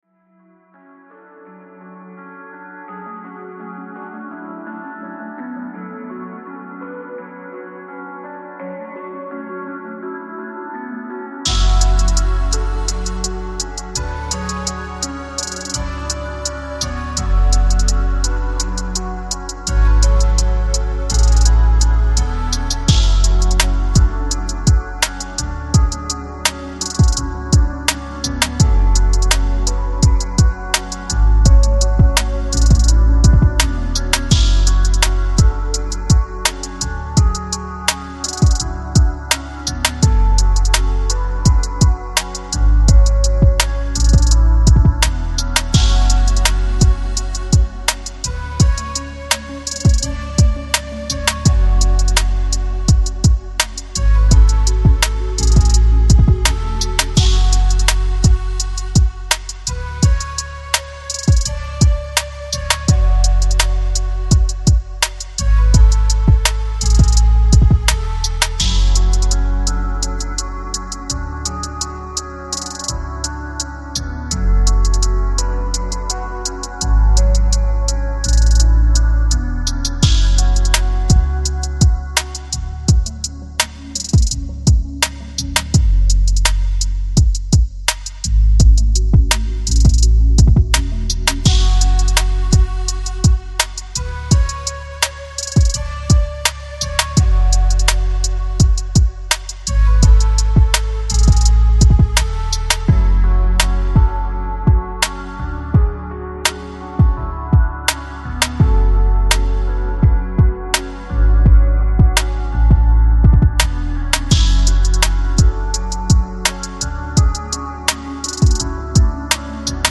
Жанр: Lo-Fi, Lounge, Chill Out, Downtempo